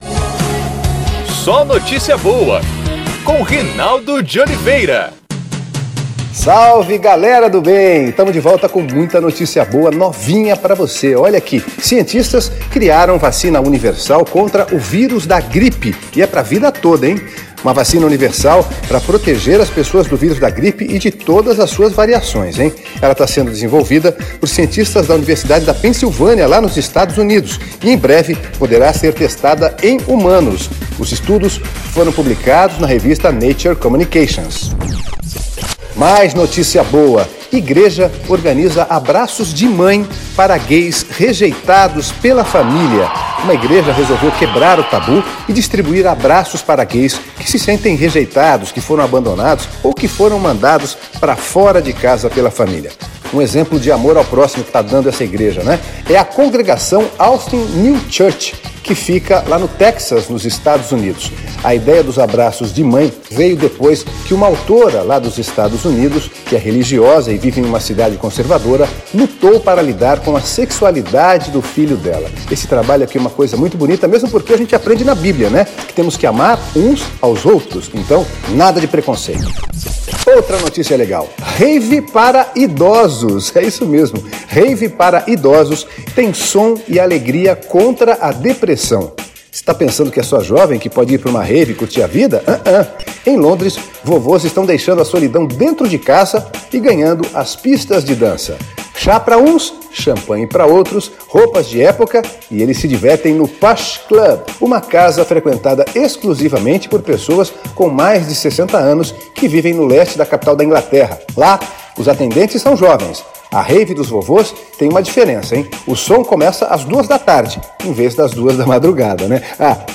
É o podcast SóNotíciaBoa, nosso programa de rádio.